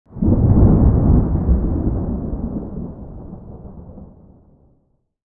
thunderfar_4.ogg